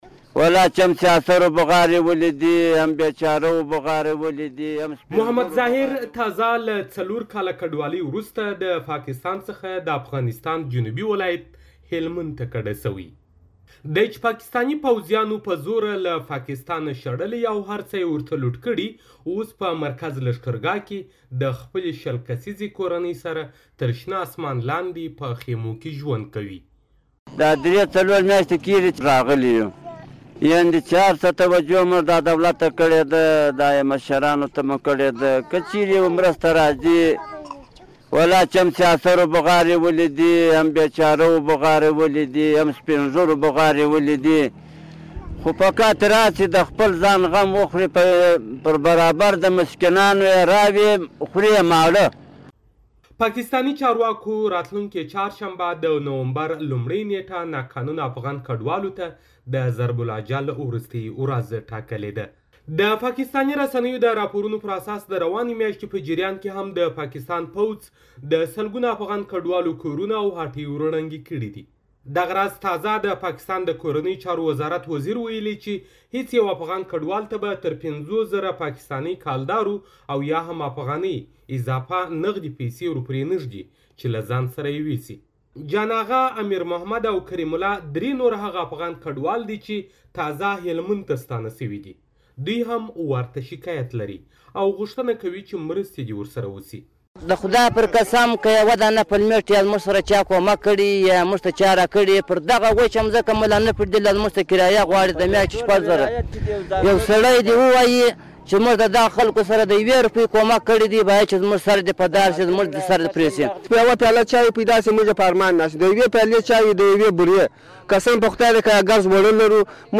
د کډوالو راپور